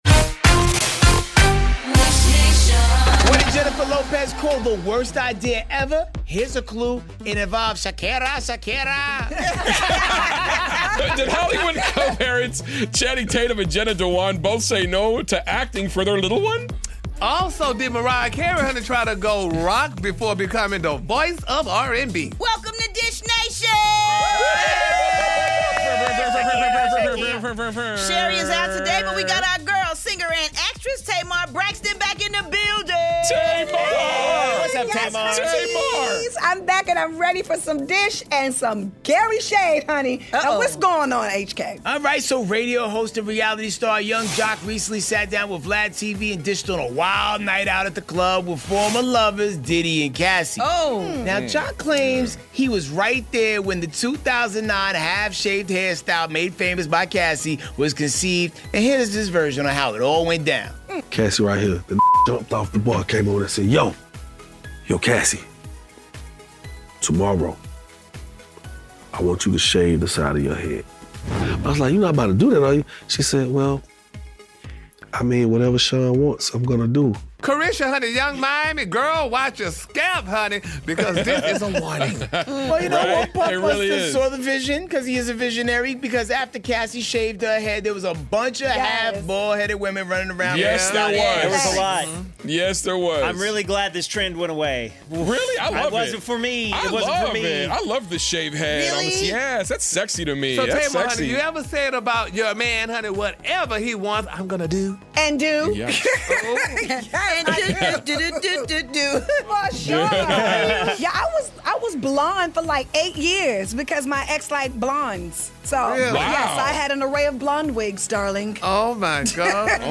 Jennifer Lopez said sharing the Super Bowl stage with Shakira was the "worst idea in the world" and Yung Joc reveals how Diddy told Cassie to shave her head in the club. Our girl Tamar Braxton co-hosts with us, so tune in to today's Dish Nation for some hot celeb dish!